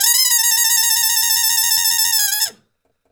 Index of /90_sSampleCDs/E-MU Formula 4000 Series Vol. 1 - Hip Hop Nation/Default Folder/Trumpet MuteFX X